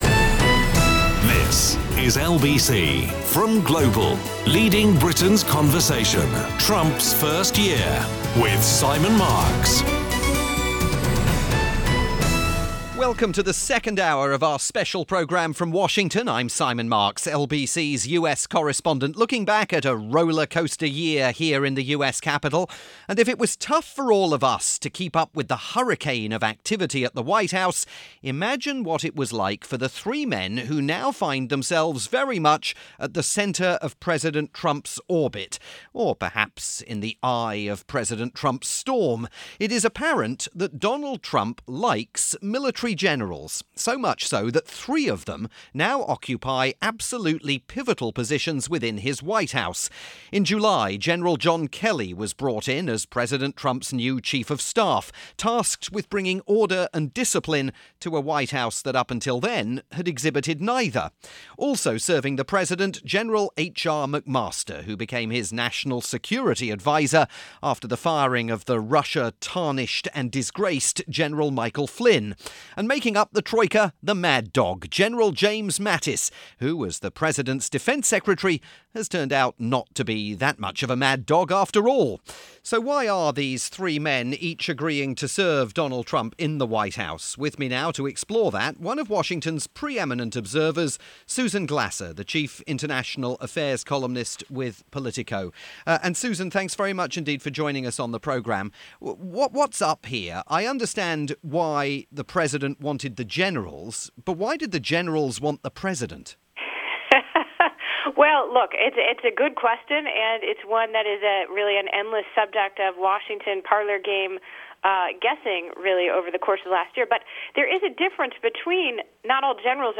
Why do the three key Generals serving in President Trump's Cabinet (Chief of Staff John Kelly, National Security Adviser HR McMaster and Defense Secretary James Mattis) stand by him? The guest is Susan Glasser of Politico.